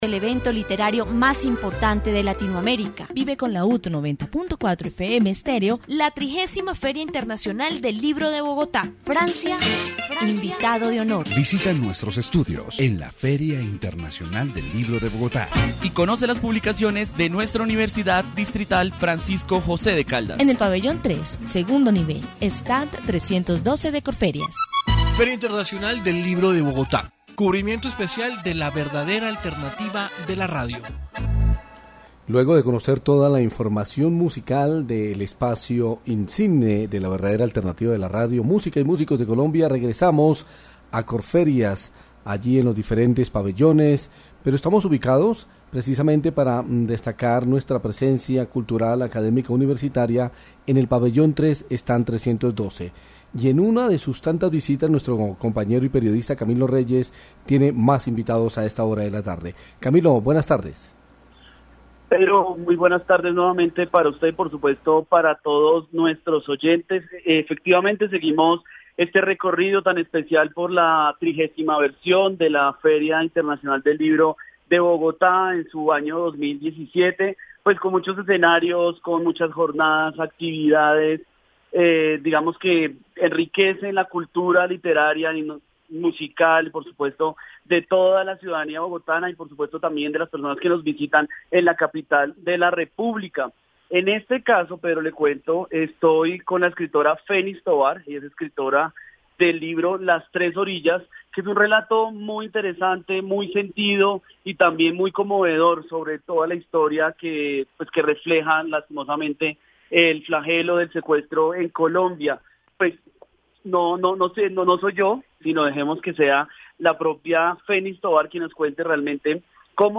Feria del Libro 2017. Informe radial